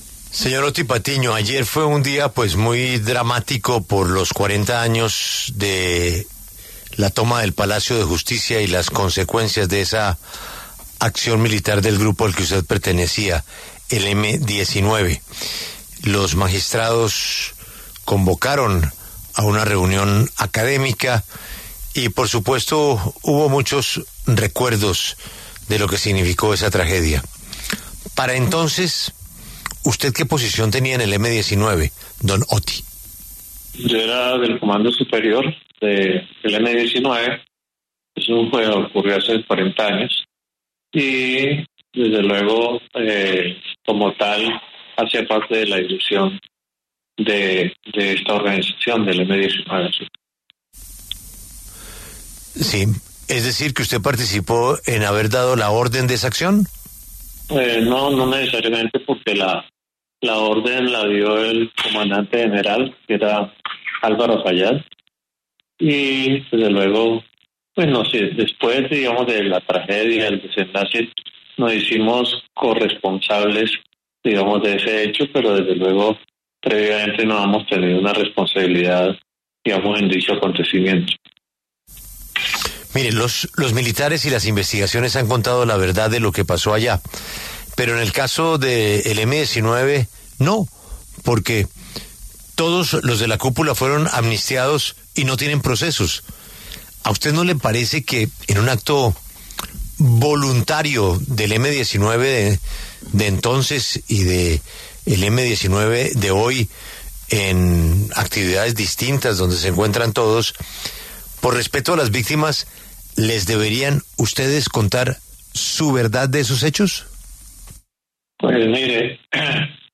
Otty Patiño, consejero comisionado para la Paz, conversó con La W sobre la toma al Palacio de Justicia en el marco de su conmemoración, pues recordemos que al momento de los hechos hacía parte del Comando Superior del M-19.